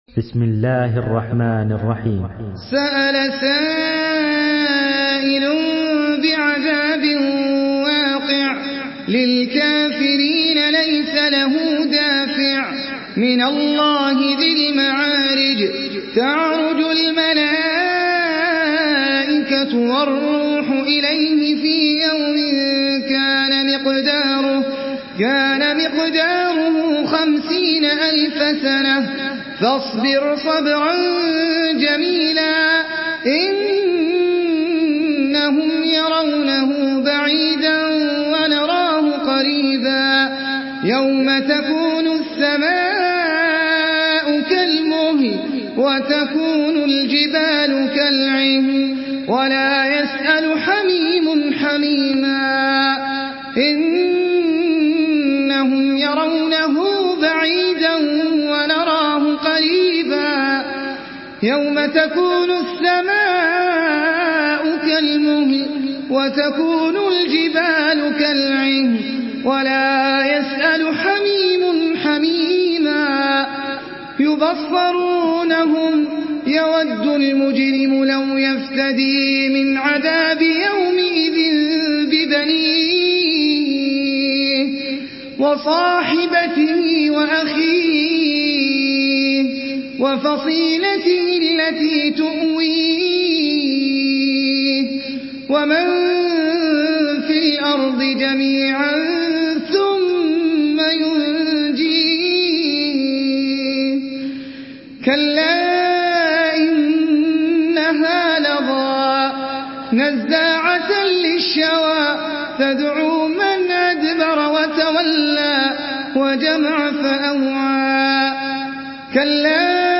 Surah Mearic MP3 by Ahmed Al Ajmi in Hafs An Asim narration.
Murattal Hafs An Asim